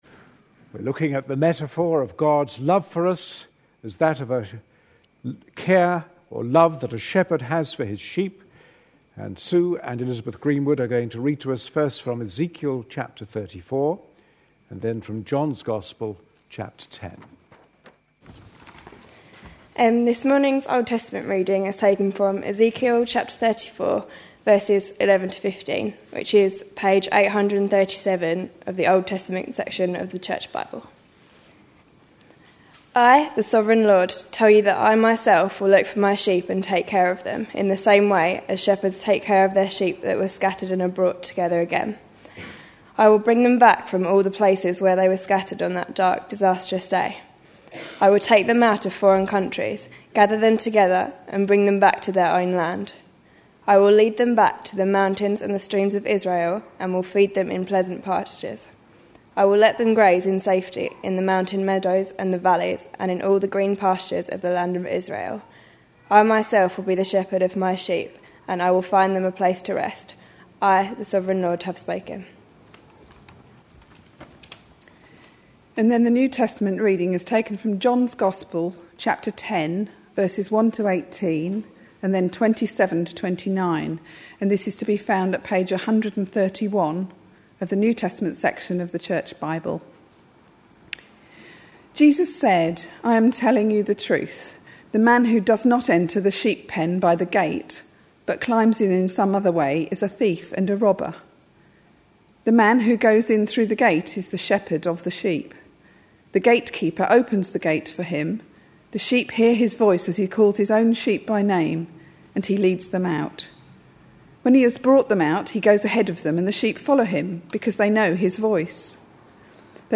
A sermon preached on 3rd April, 2011, as part of our A Passion For.... series.